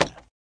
woodice.ogg